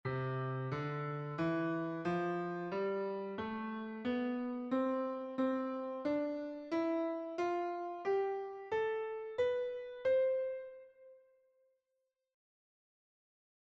L'illustration ci-dessous montre une gamme de Do à Do en clé de Fa (portée du bas), puis de Do à Do en clé de Sol (portée du haut), avec les noms des notes en syllabique et en lettres.
piano_C3_to_C5.mp3